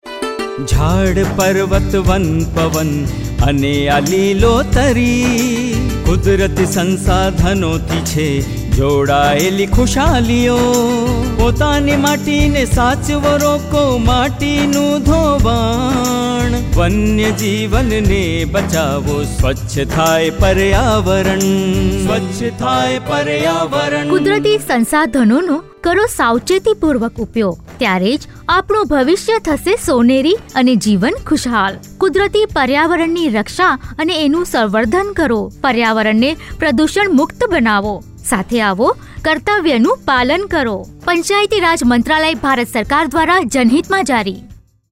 64 Fundamental Duty 7th Fundamental Duty Preserve natural environment Radio Jingle Gujrati